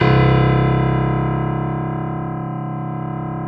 55p-pno02-C0.wav